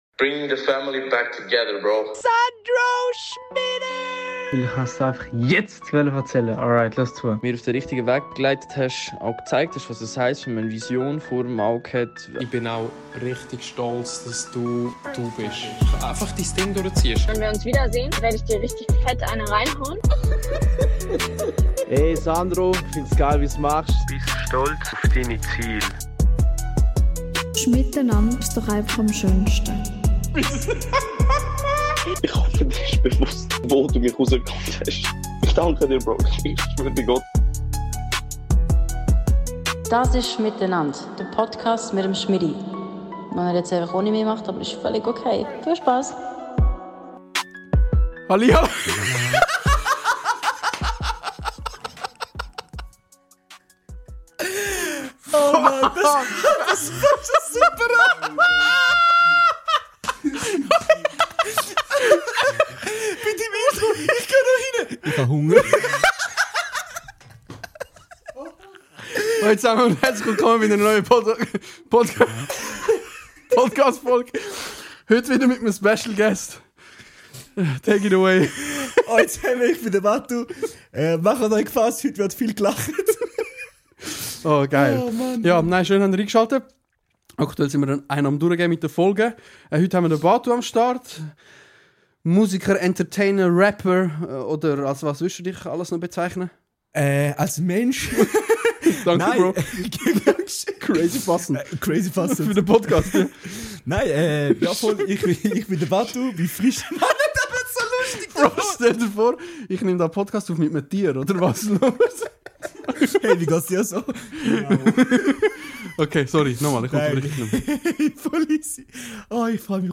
Öpe so viel Lachflashs hemmer die Folg gmeinsam gha.